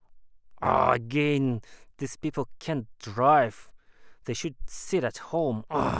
emotional-speech